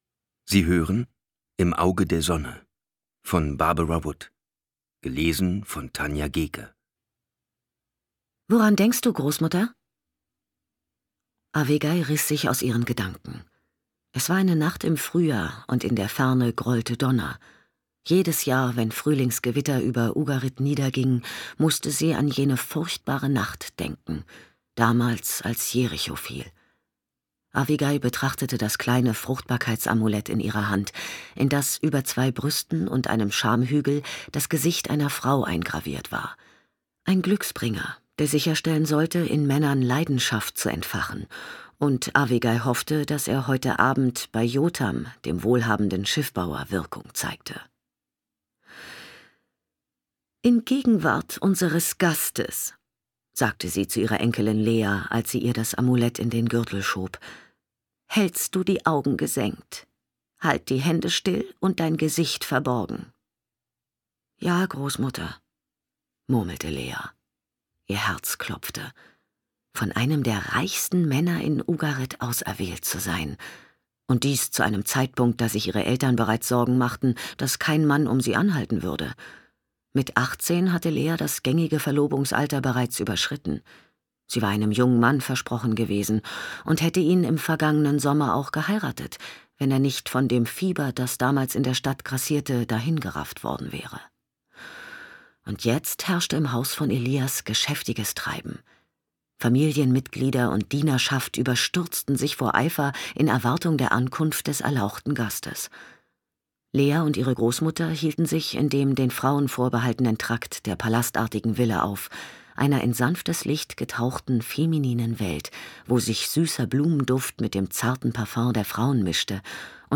Schlagworte Ägypten • Ägypten (Alt-Ä.); Romane/Erzählungen • Heilkunst • Historischer Roman • Hörbuch; Historische Romane/Erzählungen • Hörbuch; Literaturlesung • Kanaan • Liebe